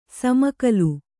♪ samakalu